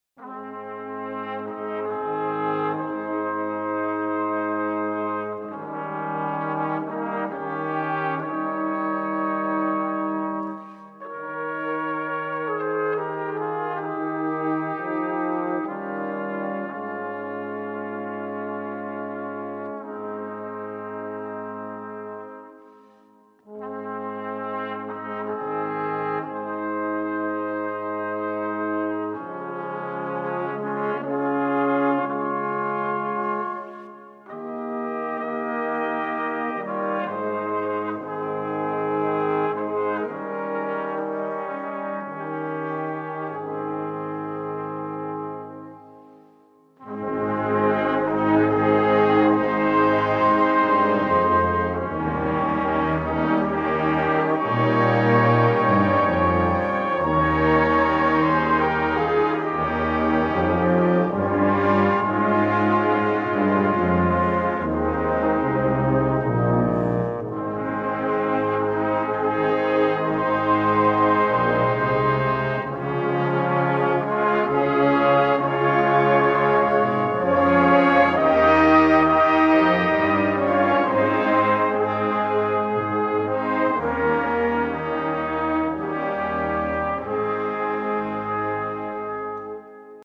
Gattung: Weise
Besetzung: Blasorchester